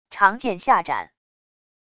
长剑下斩wav音频